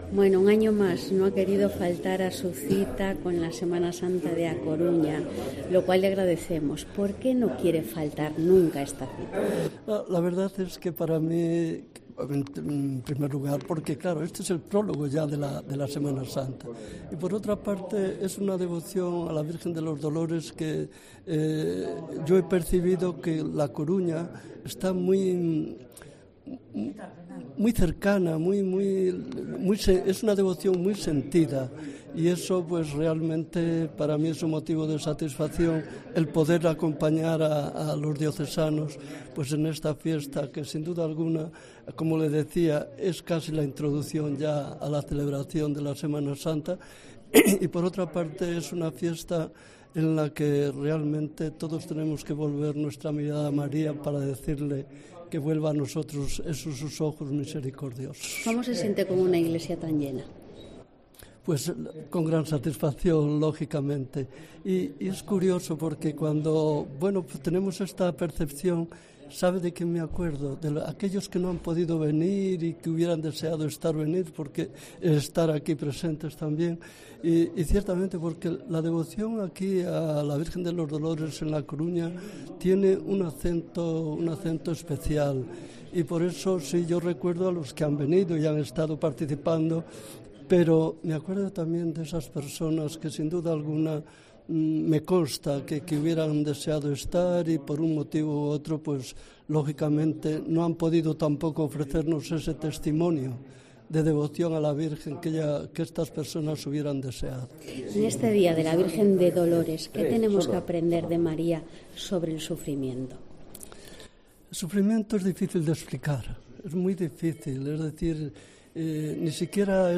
Entrevista arzobispo de Santiago, monseñor Julián Barrio